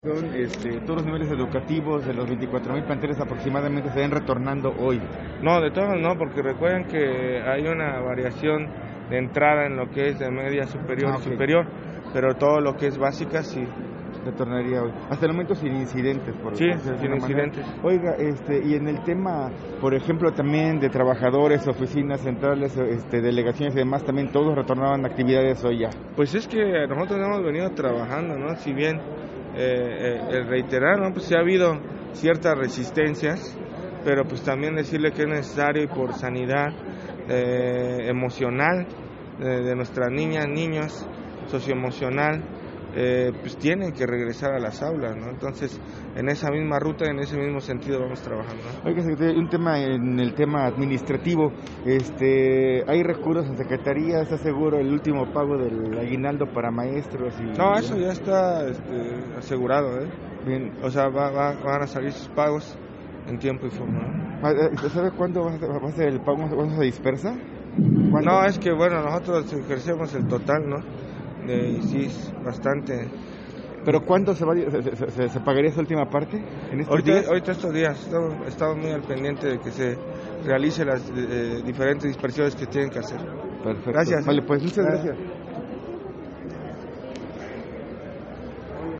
Entrevistado previo a encabezar el primer homenaje a la bandera del 2022 en la explanada de la secretaría, el funcionario estatal reconoció el respaldo y disposición de los maestros, así como de las autoridades de los planteles y padres de familia para este regreso.